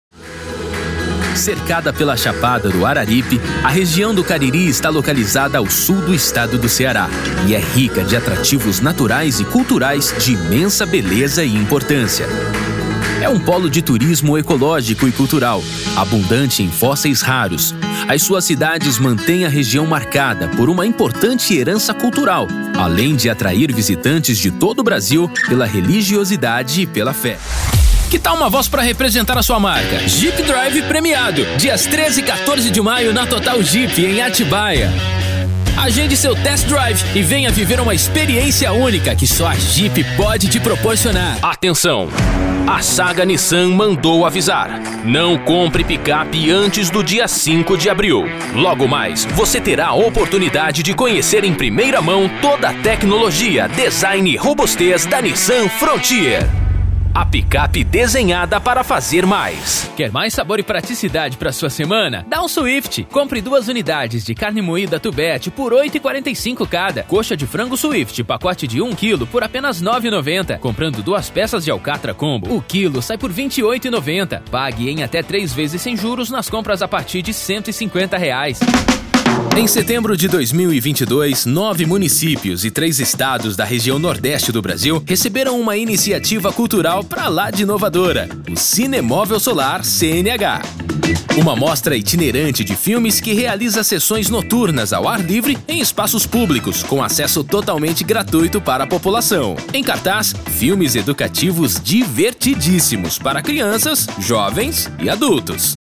Spot Comercial
Vinhetas
Padrão
Impacto
Animada